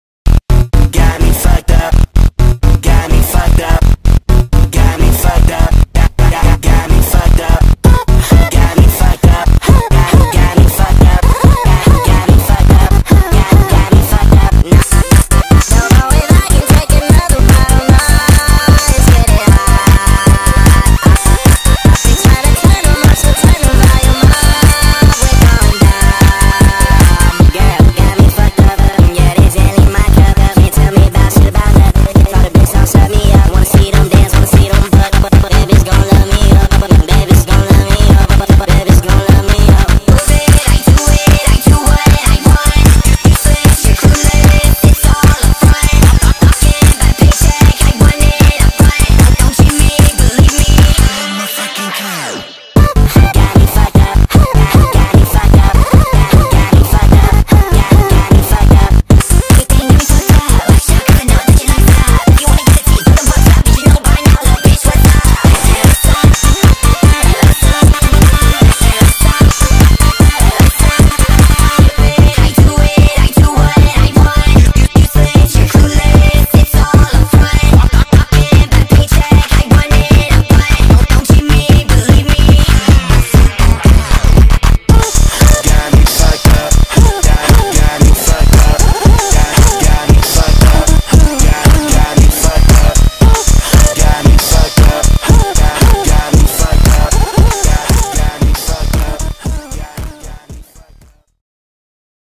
Sped Up TikTok Remix